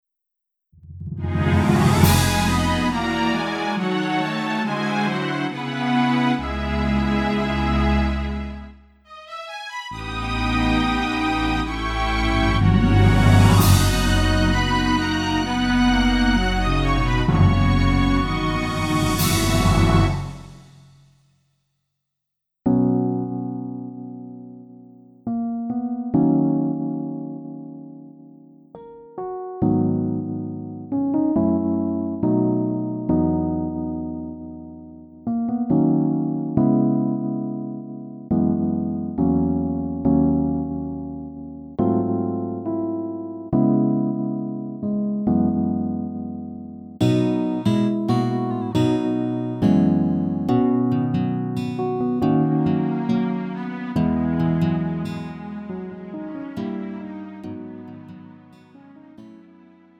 음정 -1키 4:48
장르 가요 구분 Lite MR
Lite MR은 저렴한 가격에 간단한 연습이나 취미용으로 활용할 수 있는 가벼운 반주입니다.